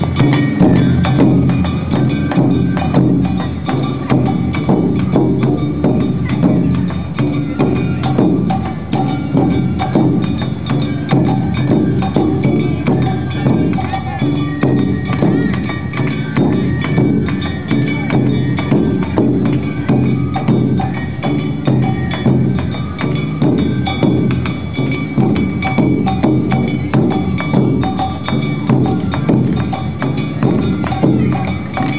丁度鳴門の阿波踊りのメインの日で、
本来この桟敷席は８００円ですが、本日最終日　終わり頃から無料開放、桟敷席でゆっくり観覧しました。